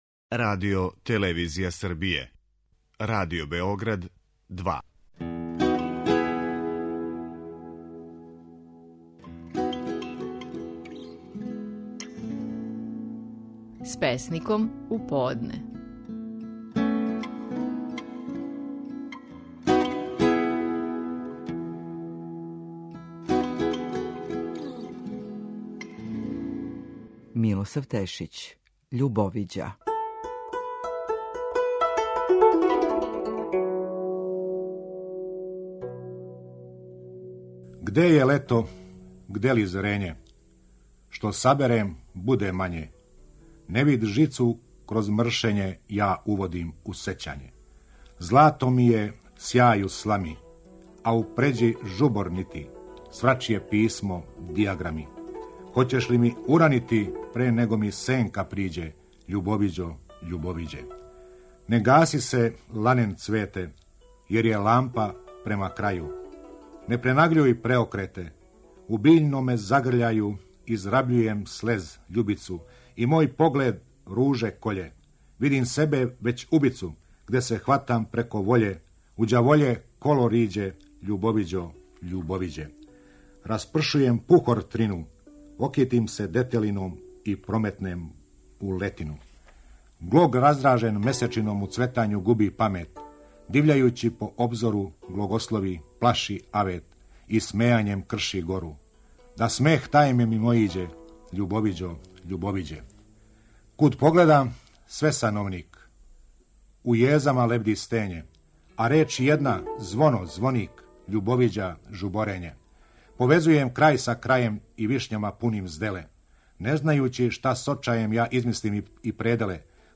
Стихови наших најпознатијих песника, у интерпретацији аутора.
Милосав Тешић казује песму „Љубовиђа".